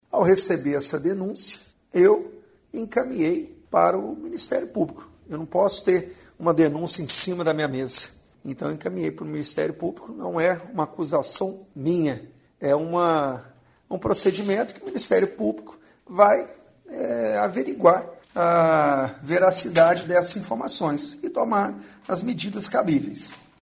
O vereador foi procurado por nossa reportagem e questionado sobre o porquê da denúncia, respondeu em áudio por aplicativo de mensagens.
Vereador José Damato